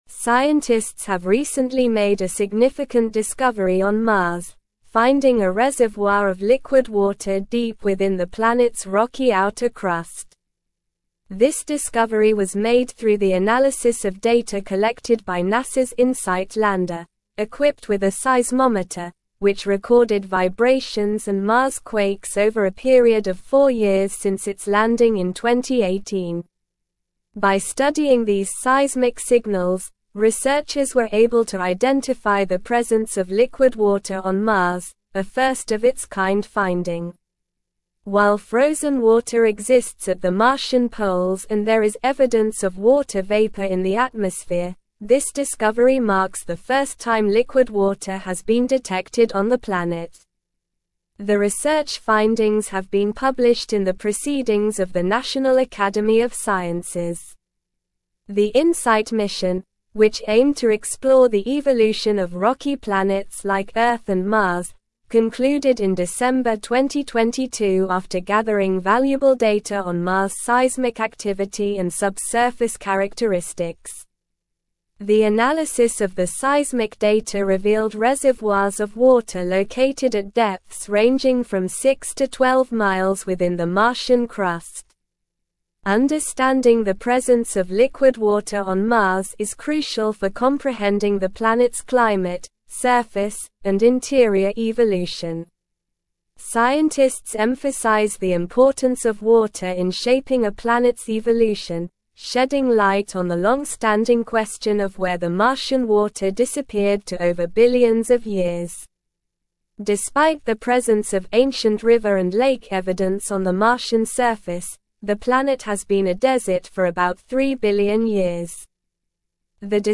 Slow
English-Newsroom-Advanced-SLOW-Reading-Discovery-of-Liquid-Water-Reservoir-on-Mars-Revealed.mp3